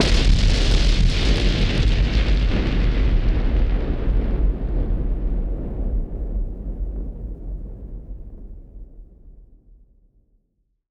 BF_SynthBomb_B-05.wav